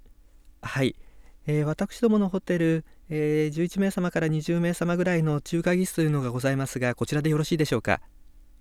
実際の話し言葉に見られる言い淀み・言い直し・語尾の変化などを含む自然発話を、極めて静かな環境にて高音質で収録しています。
発話タスク 旅行に関する模擬会話形式による対話想定発話（非対面収録）
48k音声データ 　：WAV形式(48kHz,16bit,MONO)
サンプル1(男性)